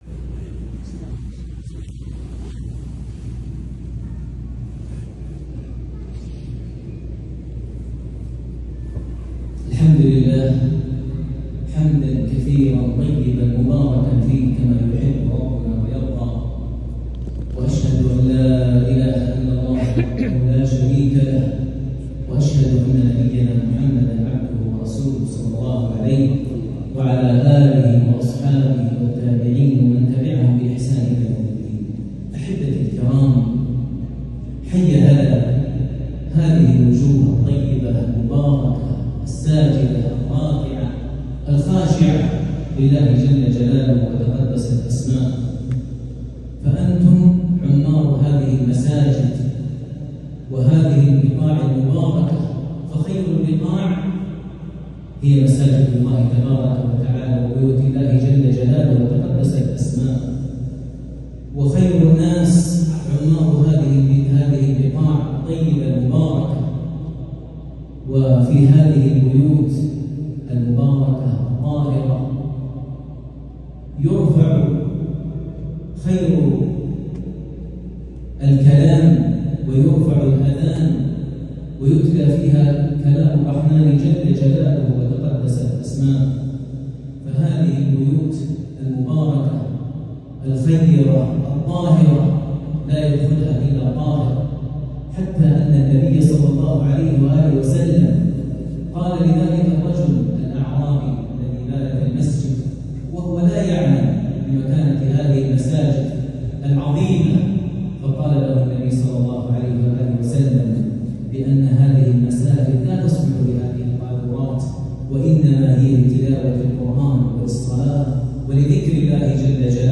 كلمة الشيخ ماهر المعيقلي بمناسبة إفتتاح مسجد الإخلاص بمكة | الجمعة 4-8-1444هـ > إمامة الشيخ ماهر المعيقلي وجهوده الدعوية داخل السعودية > المزيد - تلاوات ماهر المعيقلي